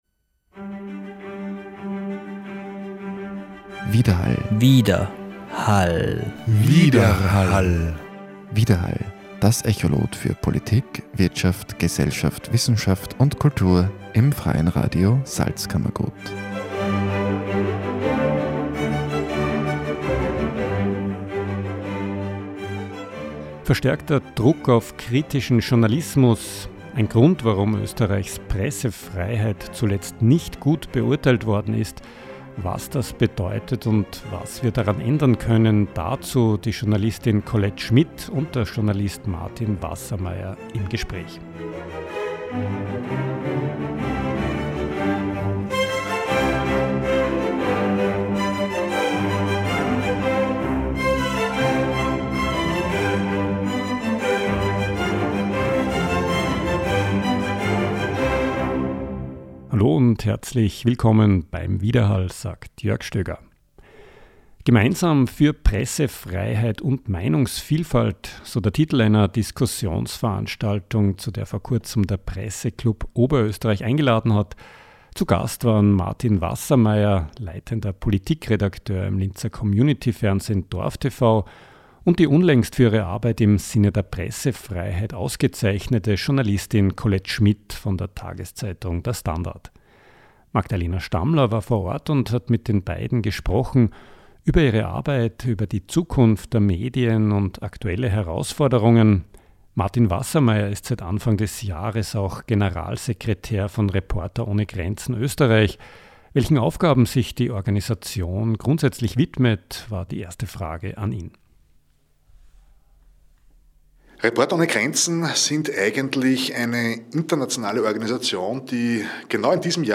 Mit beiden haben wir abseits der Diskussion gesprochen.